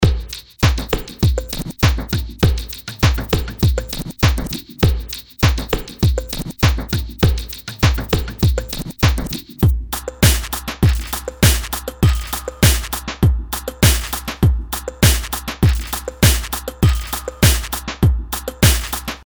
Zur Auflockerung gleich ein Audiodemo: Hier hören Sie den EZdrummer 2 von Toontrack, EZX Electronic: